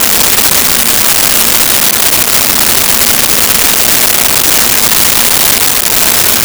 Vacuum Cleaner 01
Vacuum Cleaner 01.wav